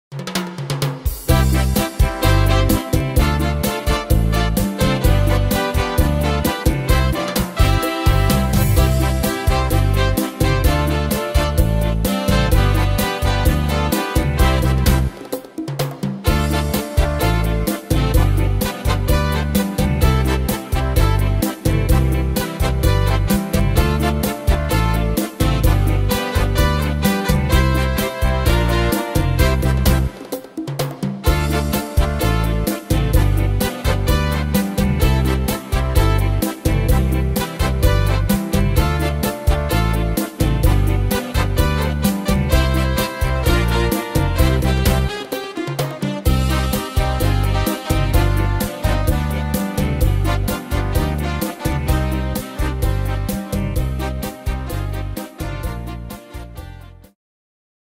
Tempo: 128 / Tonart: C-Dur